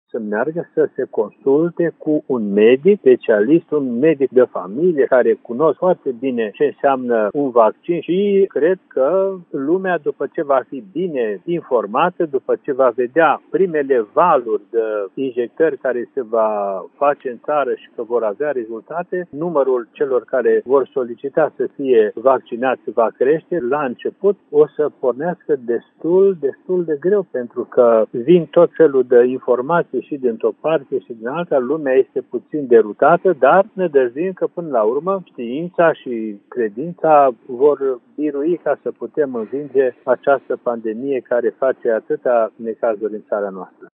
Mitropolitul-Banatului-vaccinare.mp3